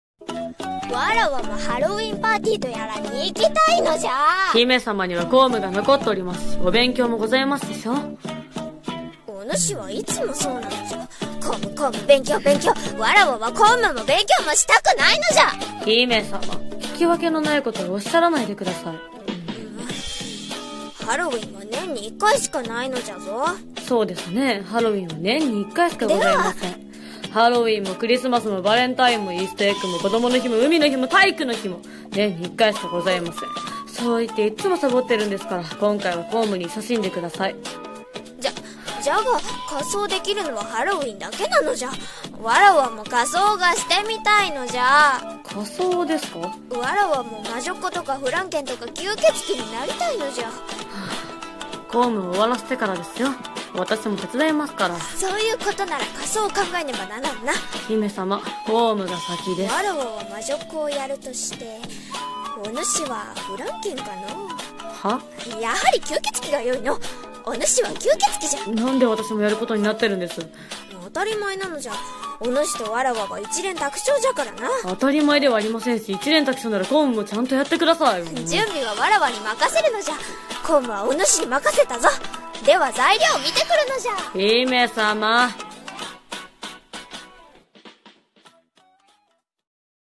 【声劇 掛け合い】